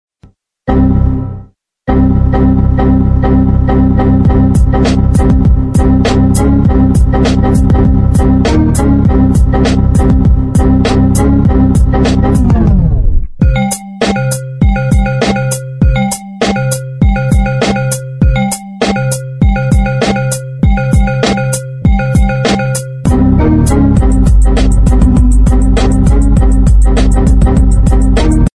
Windows Errors Mix